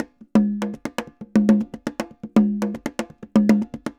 Congas_Candombe 120_2.wav